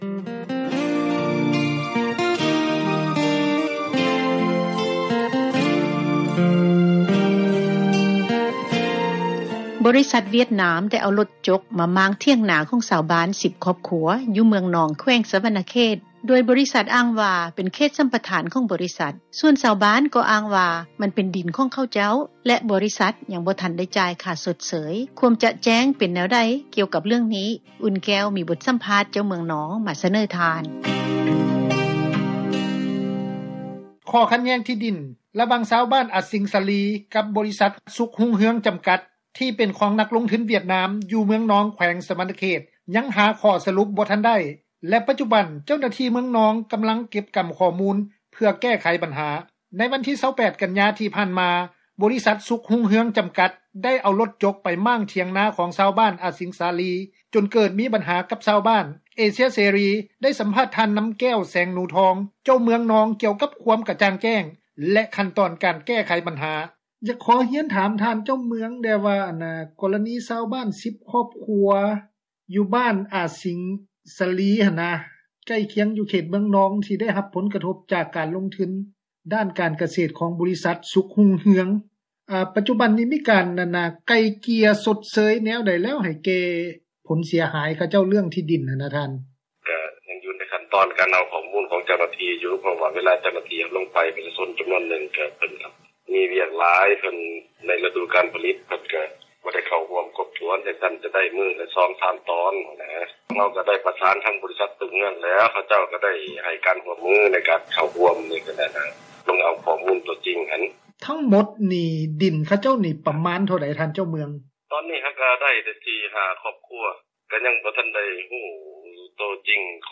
ວິທຍຸເອເຊັຽເສຣີ ໄດ້ມີໂອກາດ ສັມພາດ ທ່ານ ນຳແກ້ວ ແສງໜູທອງ, ເຈົ້າເມືອງ ເມືອງນອງ, ກ່ຽວກັບຄວາມກະຈ່າງແຈ້ງ ຂັ້ນຕອນ ການແກ້ໄຂບັນຫາ.